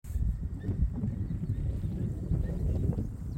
малый подорлик, Clanga pomarina
СтатусПара в подходящем для гнездования биотопе